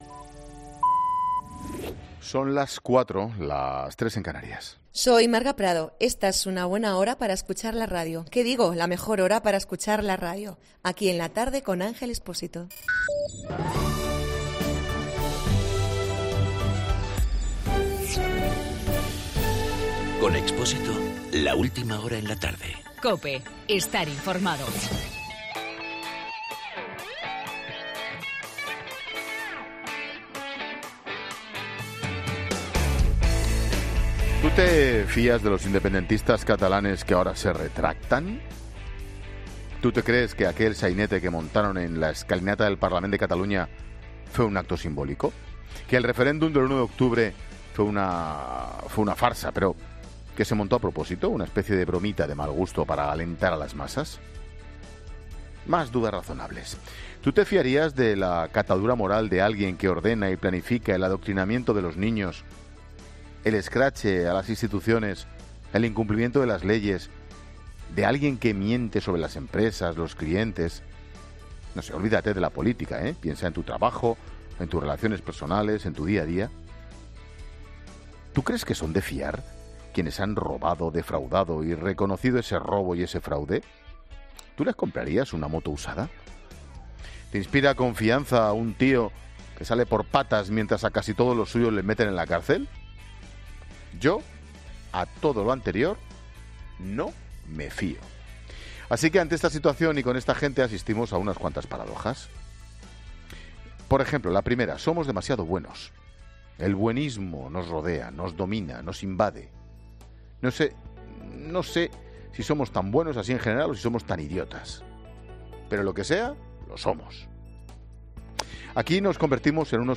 AUDIO: Ángel Expósito analiza en su monólogo de las 16 horas la situación de la crisis catalana.